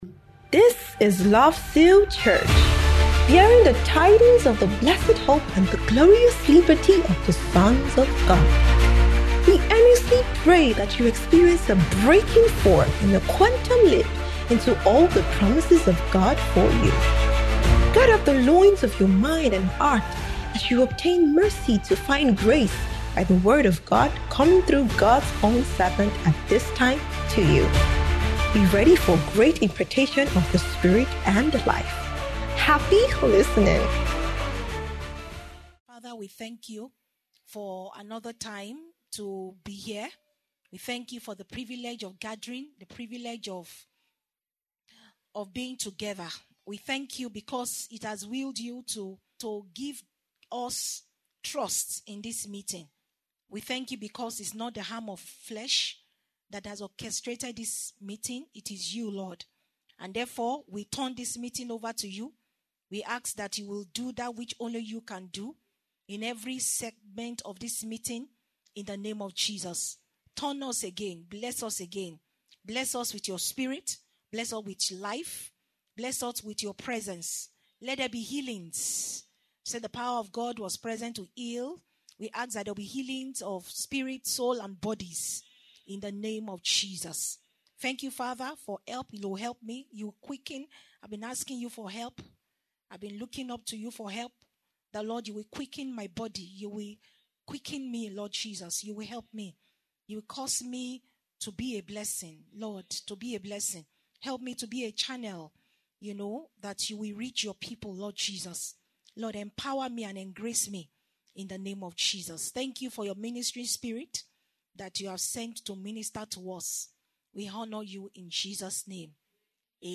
Marriageable Singles’ Ingathering